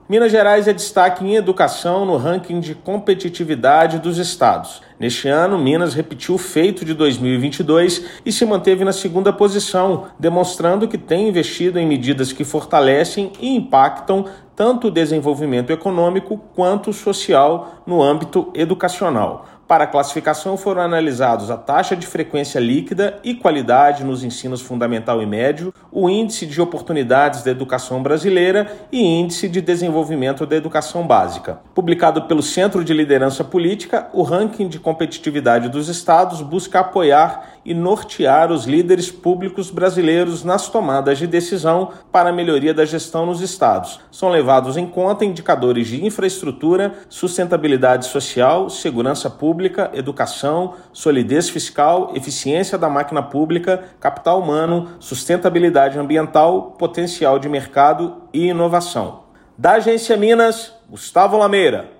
Ranking de Competitividade dos estados tem como objetivo pautar a atuação dos líderes públicos na melhoria da competitividade e da gestão pública dos estados. Ouça matéria de rádio.